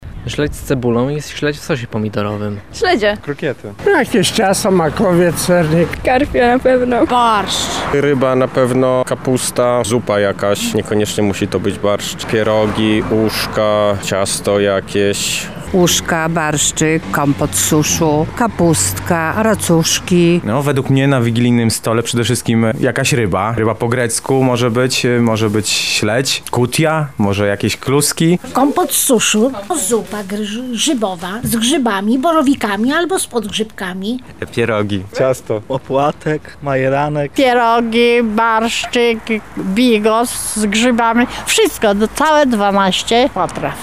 [SONDA] Na jakie potrawy wigilijne czekają lublinianie?
Z tego względu zapytaliśmy mieszkańców Lublina, jakie posiłki są przez nich najbardziej wyczekiwane i które muszą pojawić się na ich talerzach.